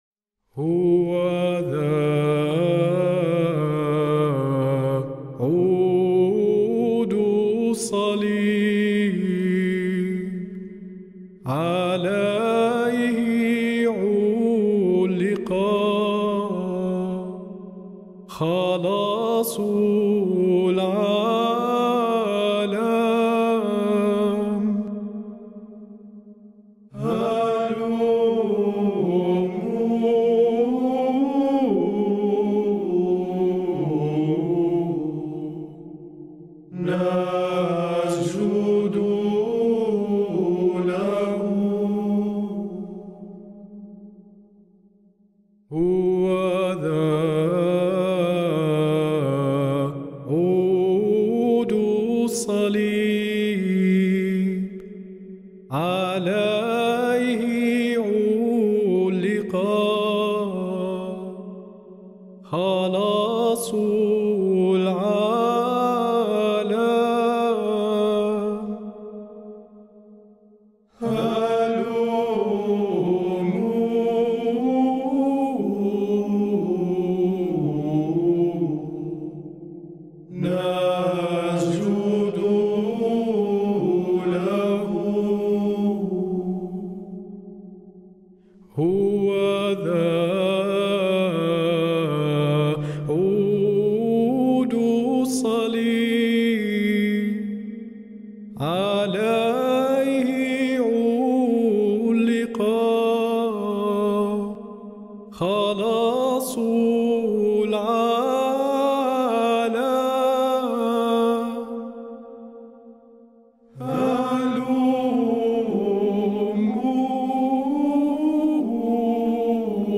هوذا-عود-الصليب-أداء-طلاب-المعهد-الإكليريكي-للبطريركية-اللاتينية-الأورشليمية.mp3